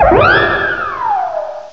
cry_not_whimsicott.aif